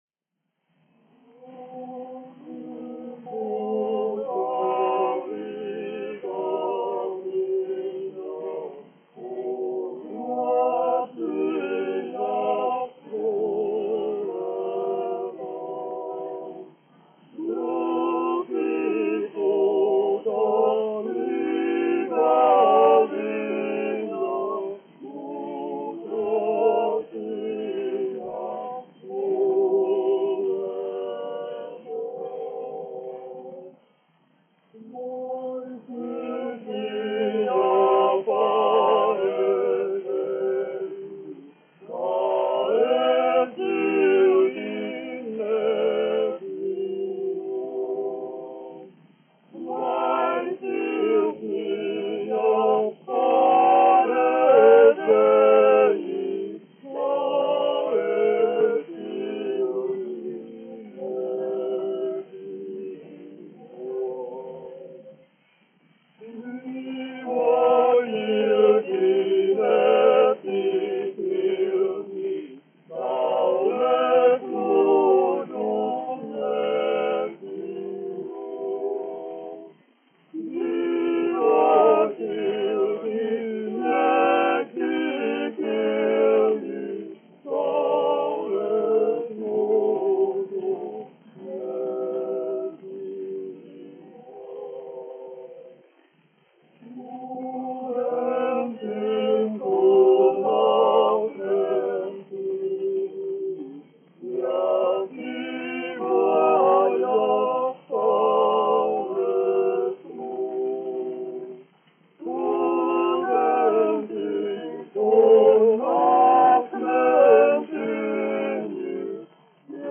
1 skpl. : analogs, 78 apgr/min, mono ; 25 cm
Vokālie kvarteti
Latviešu tautasdziesmas
Skaņuplate
Latvijas vēsturiskie šellaka skaņuplašu ieraksti (Kolekcija)